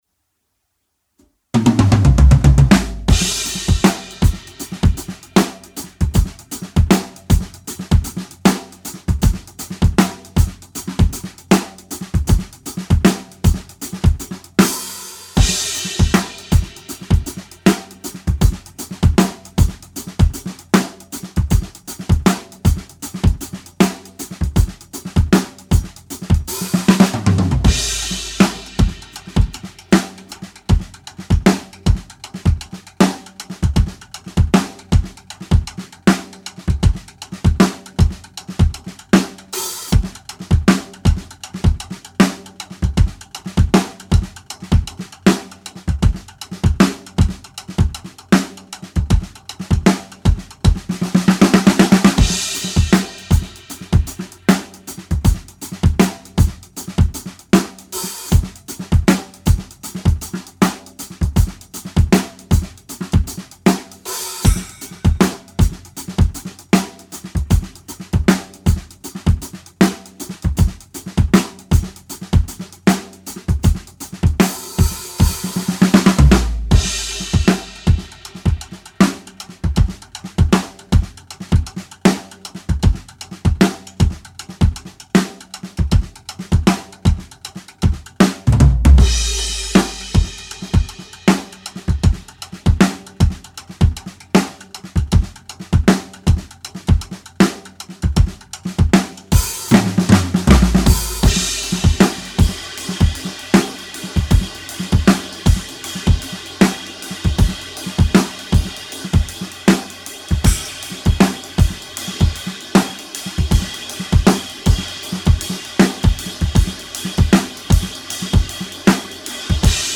Genre:Shuffle, Pop
Tempo:156.5 BPM (4/4)
Kit:Rogers 1977 Big R 22"
Mics:14 channels